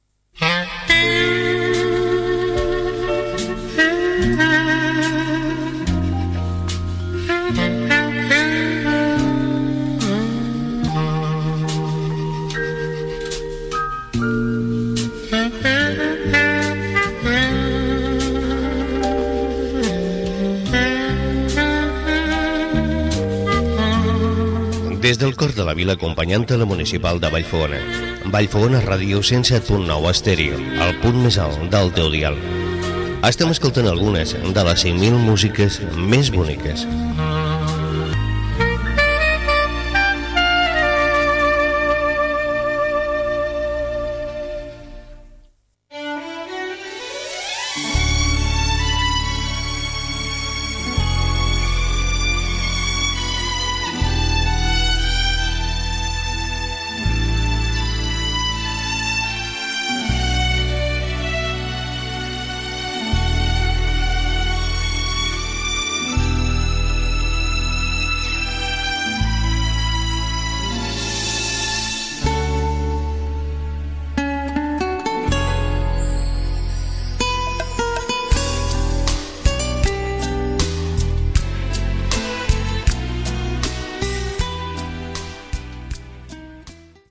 Indicatiu de l'emissora